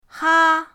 ha1.mp3